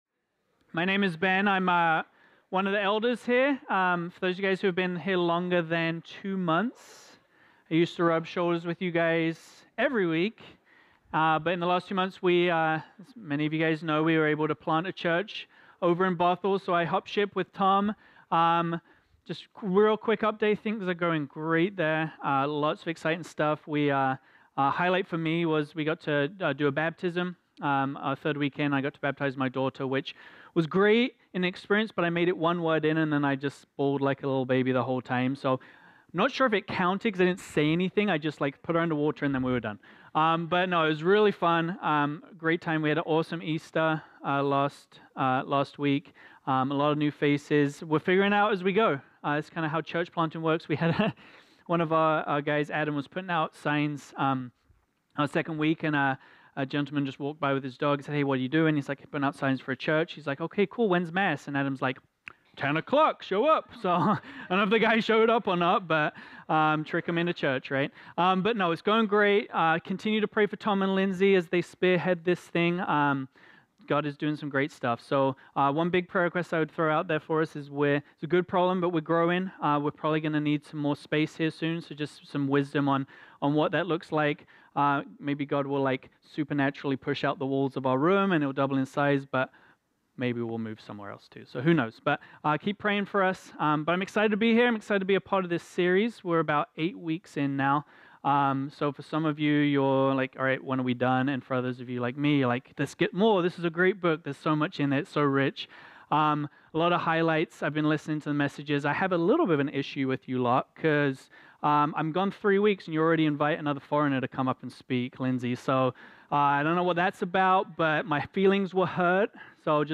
This sermon was originally preached on Sunday, April 8, 2018.